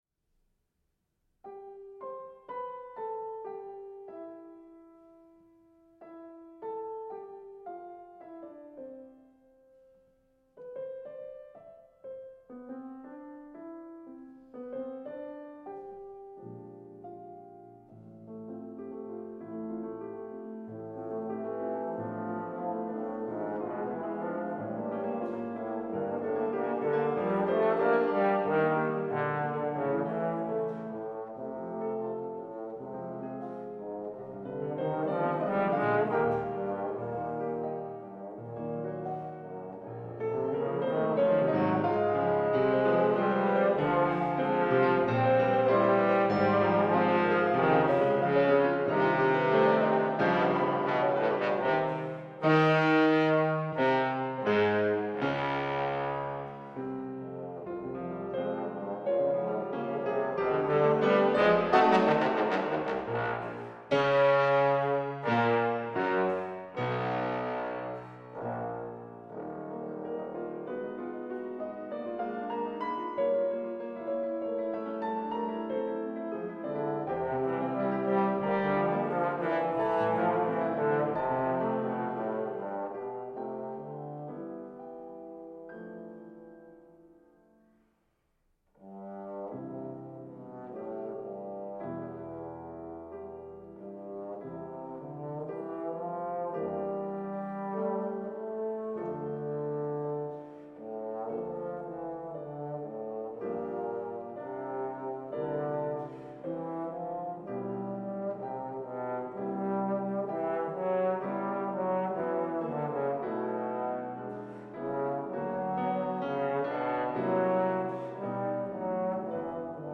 Piano
Bass trombone